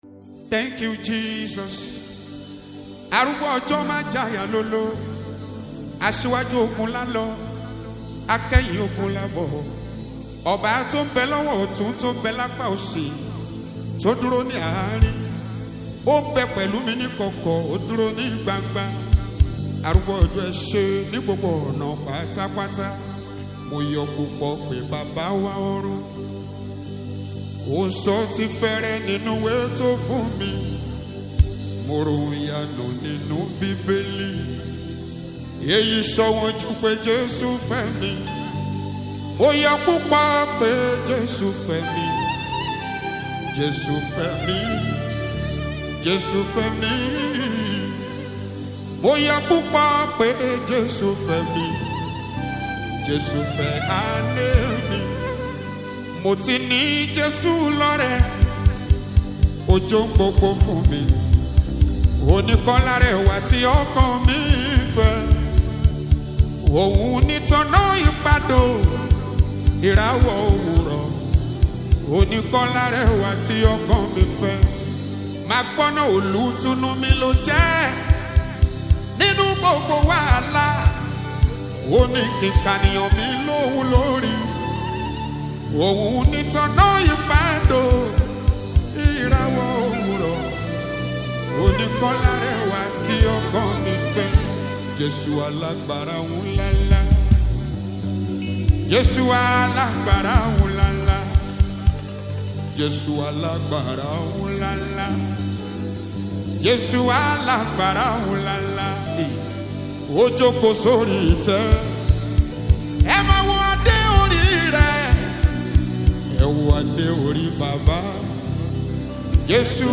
Nigerian gospel singer and minister